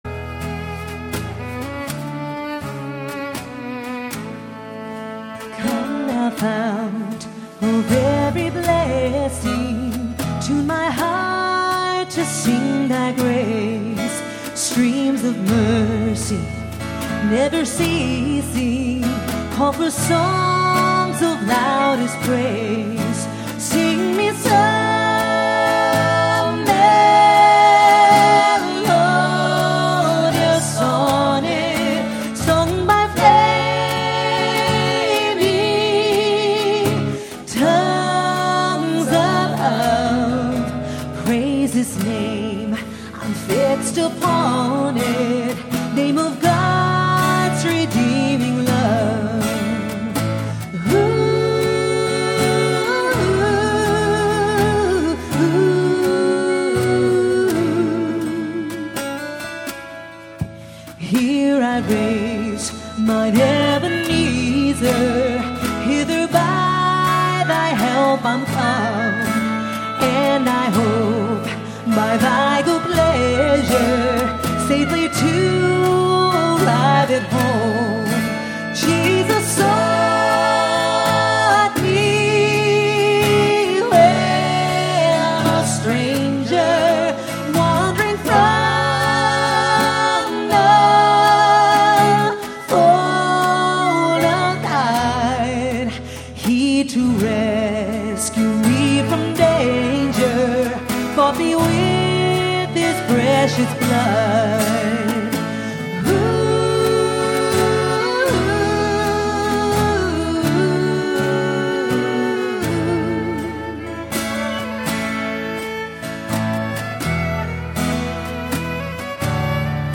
Come Thou Fount (acoustic) - July 19, 2009
Performed live at Terra Nova - Troy on 7/19/09.